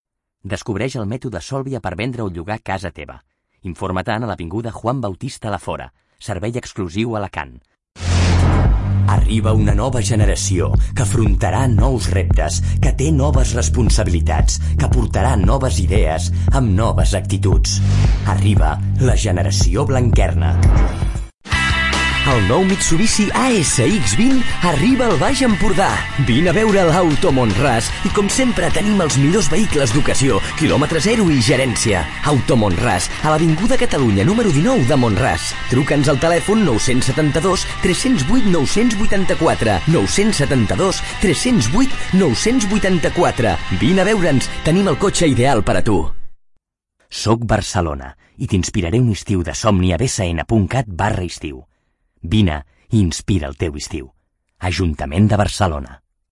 Tipo de Voz: Media (de 20 a 40 años)
Doblaje Castellano